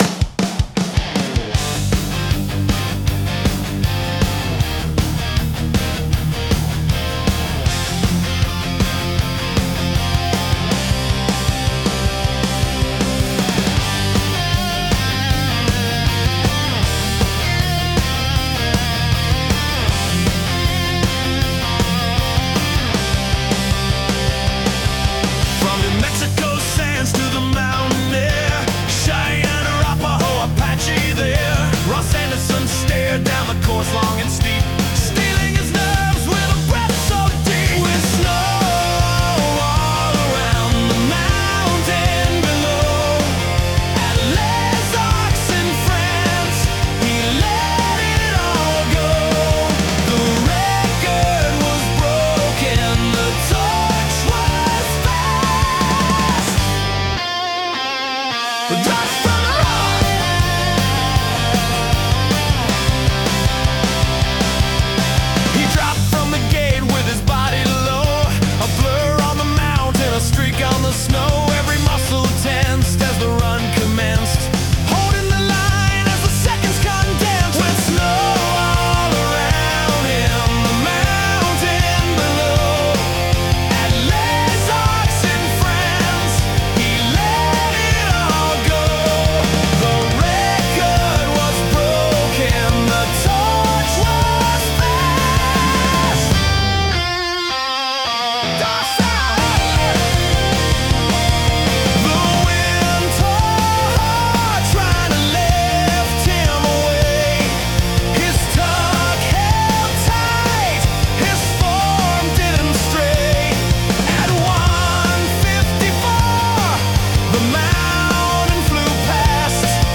a high-energy tribute to World Cup speed ski racer